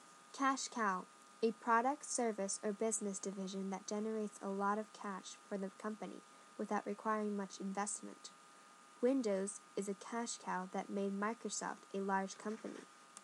英語ネイティブによる発音は下記のURLから聞くことができます。